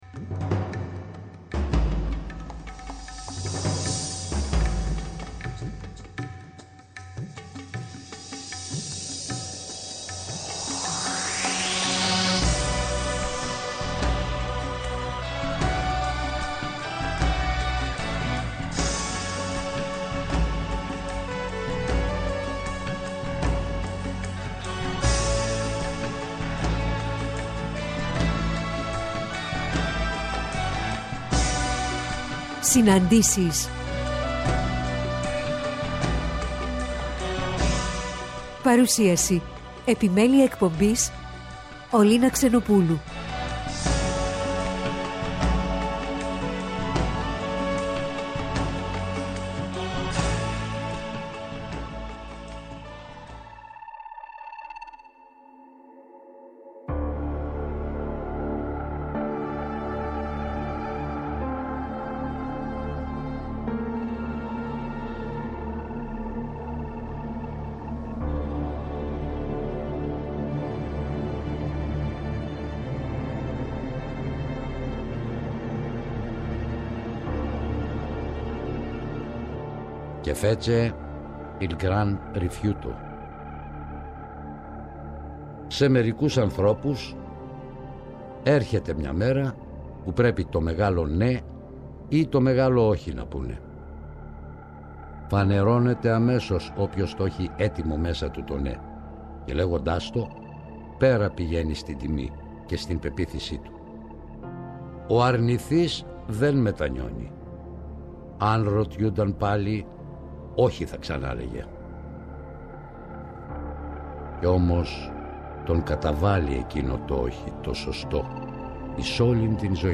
Παρουσίαση – Ραδιοφωνική Παραγωγή